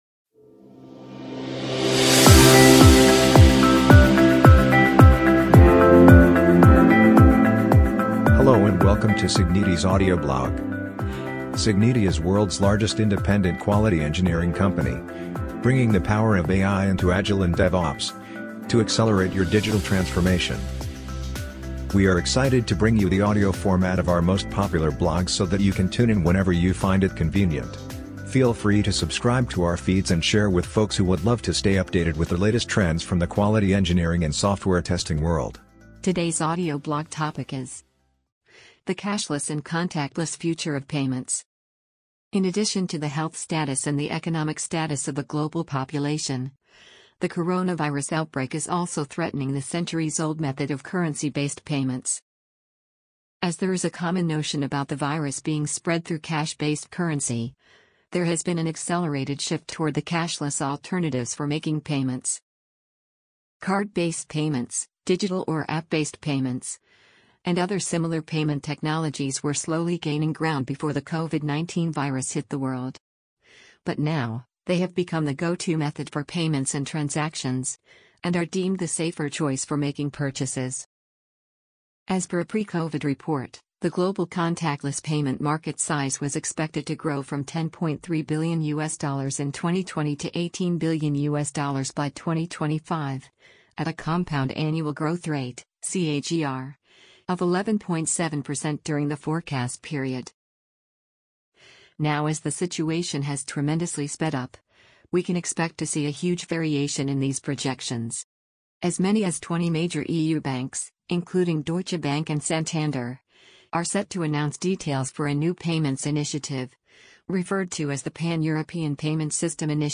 amazon_polly_14365.mp3